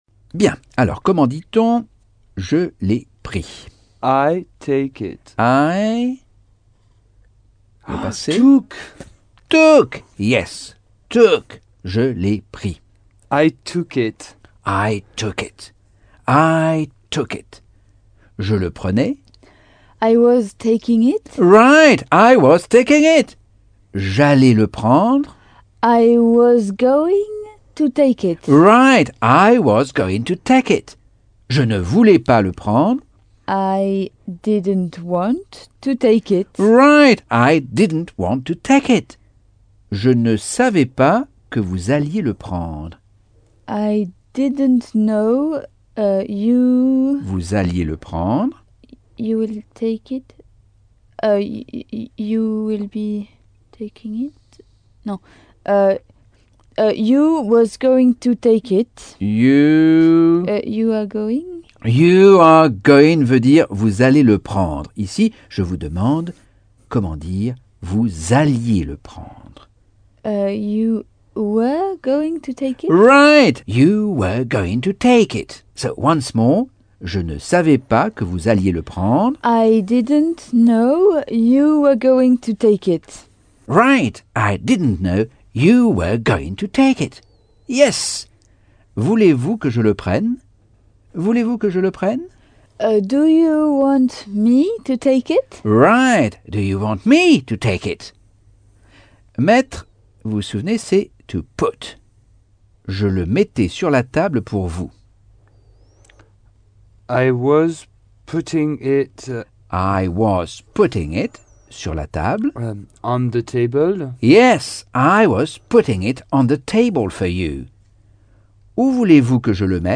Leçon 4 - Cours audio Anglais par Michel Thomas - Chapitre 8